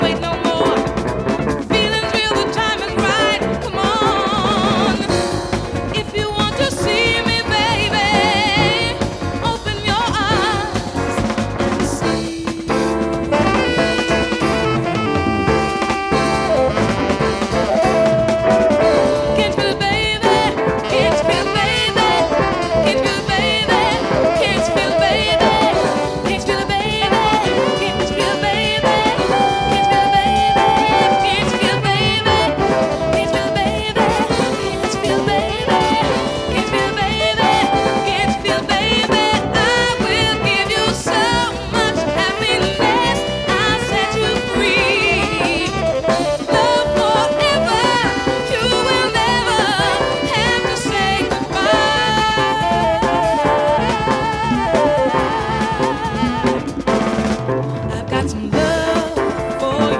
ジャンル(スタイル) RARE GROOVE / JAZZ FUNK / FUSION